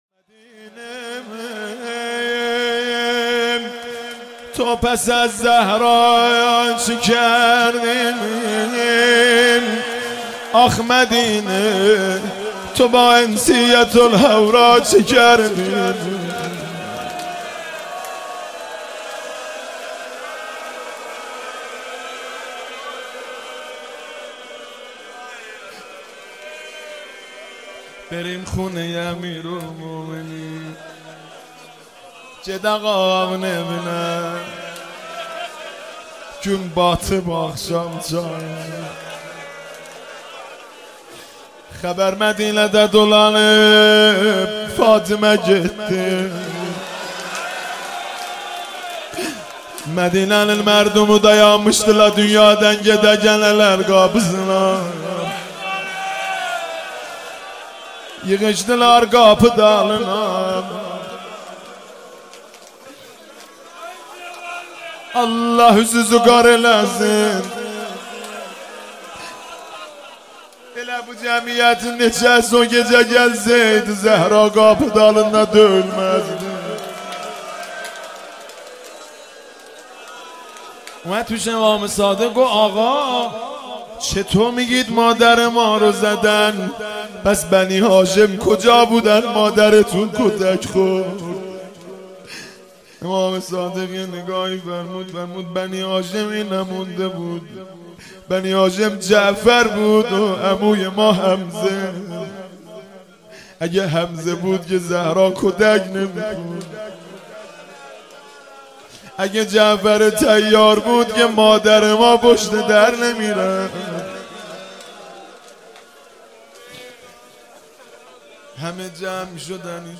مداحی جدید حاج مهدی رسولی 19 دی ماه 1398 ایام فاطمیه اول هیات ثارالله زنجان